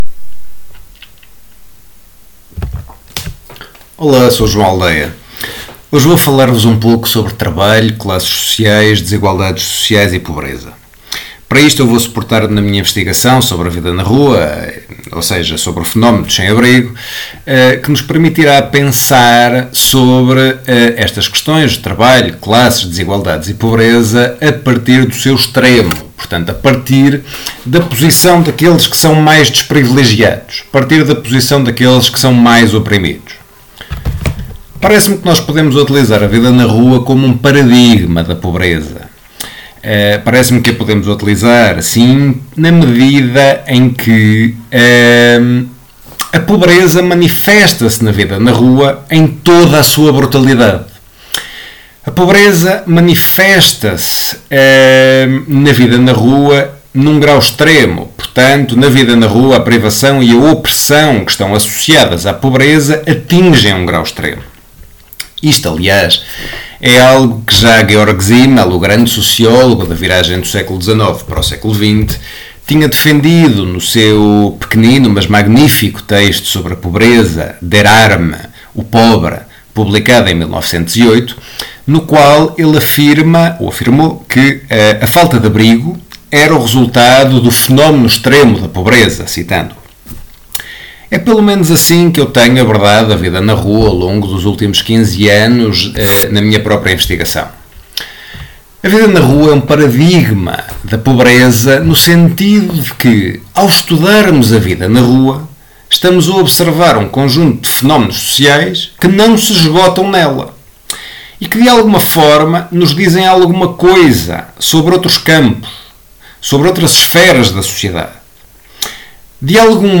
Discussão sobre o trabalho na vida na rua preparada para apoio ao estudo do tema 2.2. da unidade curricular 41056 - Sociedade Portuguesa Contemporânea, 2025/2026.